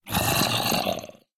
Sound / Minecraft / mob / zombie / say2.ogg
should be correct audio levels.